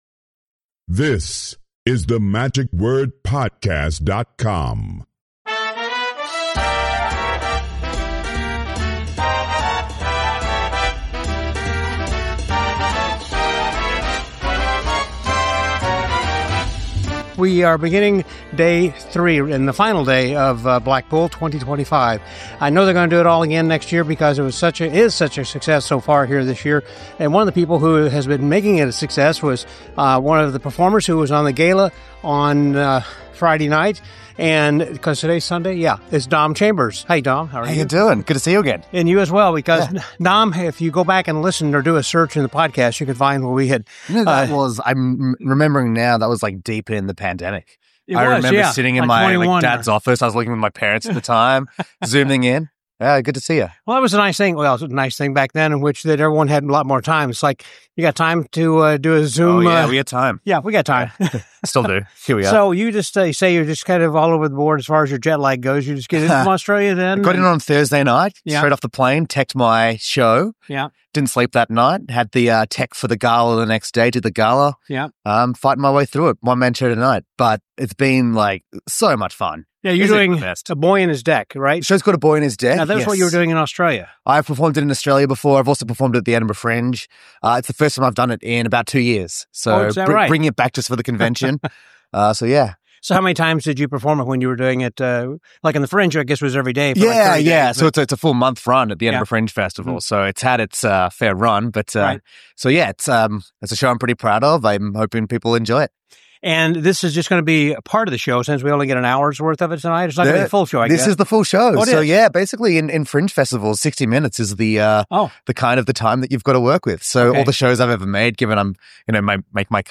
885: Blackpool 2025 - Day Three Report — The Magic Word